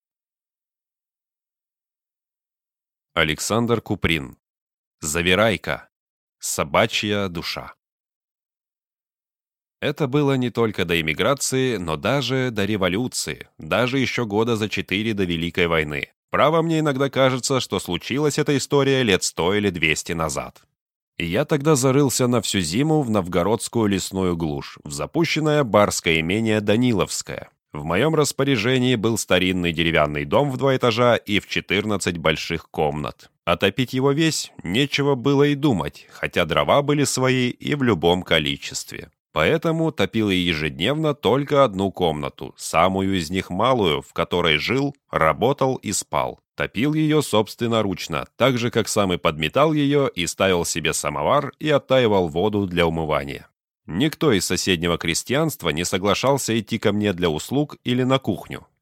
Аудиокнига Завирайка | Библиотека аудиокниг